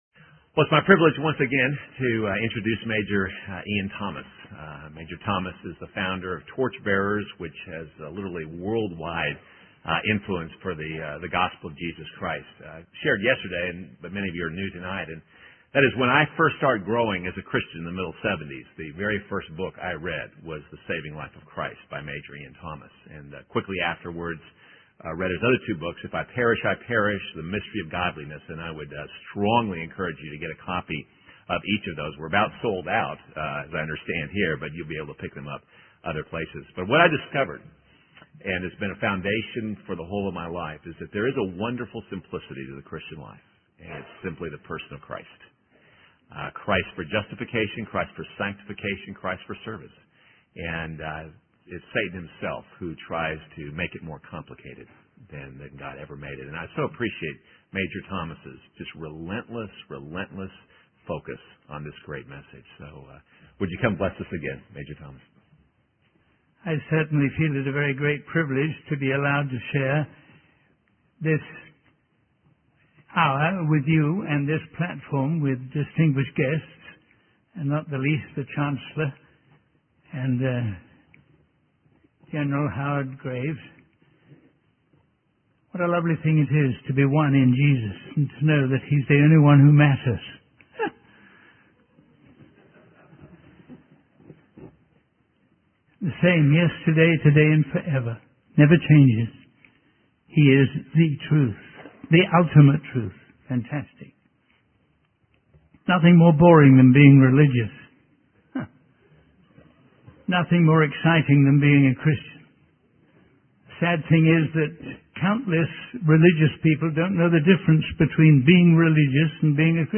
In this sermon, the speaker emphasizes the importance of obeying God's instructions and going where we are sent. He shares a story about a young crew member who hesitates to go out in a dangerous rescue mission, but is encouraged by an experienced sailor to prioritize saving lives over personal safety. The speaker relates this story to the marching orders given to believers by the Holy Spirit, urging them to use their abilities and resources to spread the gospel.